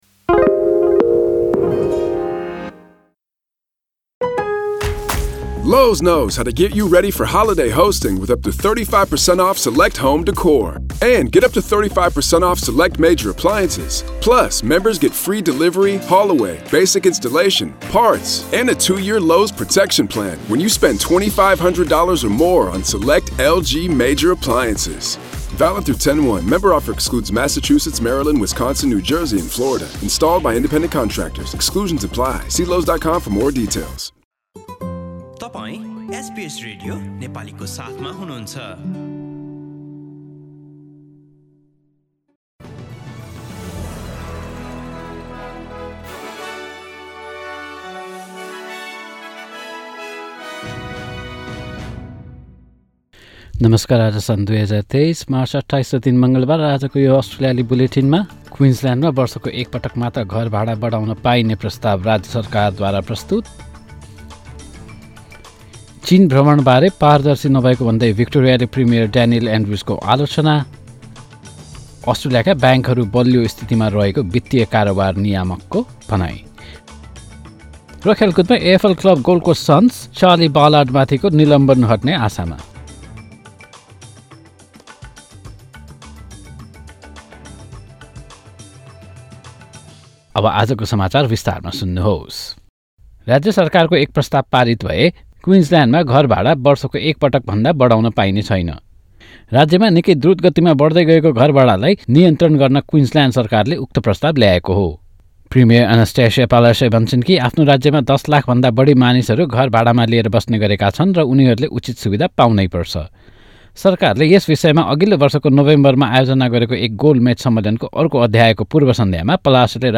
एसबीएस नेपाली अस्ट्रेलिया समाचार: मङ्गलवार २८ मार्च २०२३